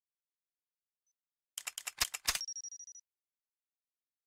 defusing.mp3